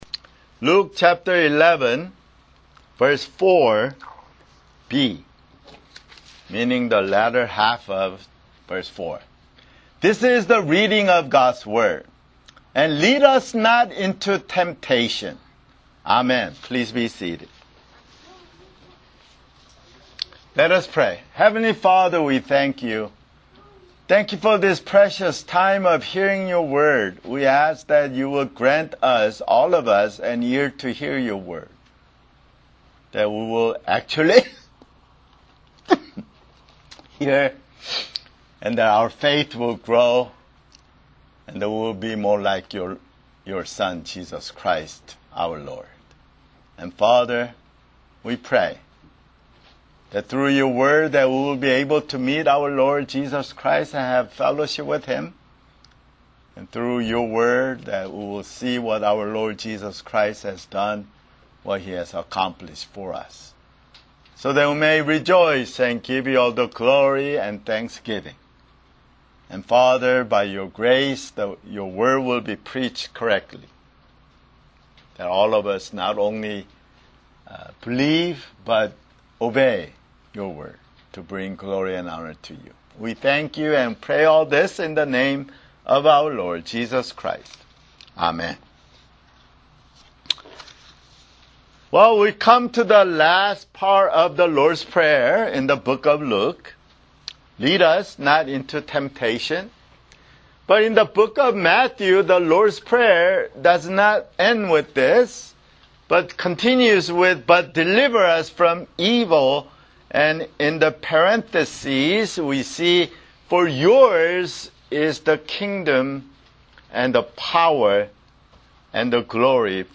[Sermon] Luke (79)